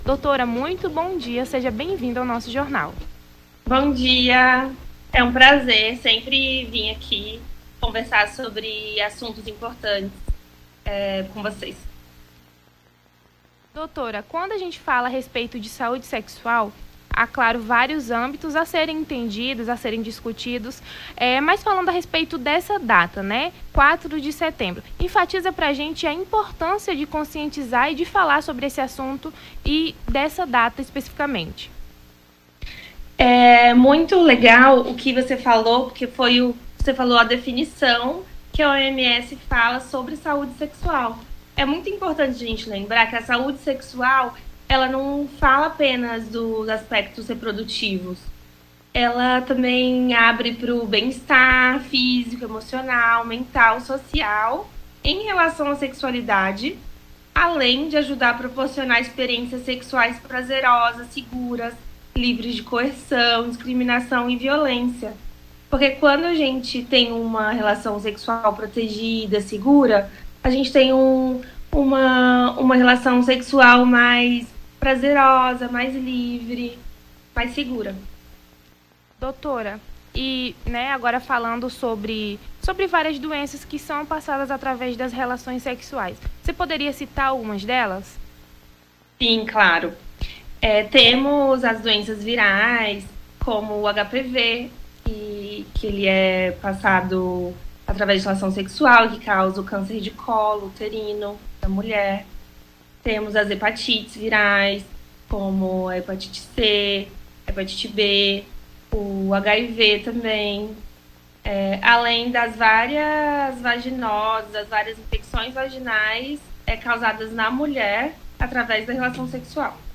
Nome do Artista - CENSURA - ENTREVISTA (SAÚDE SEXUAL) 04-09-23.mp3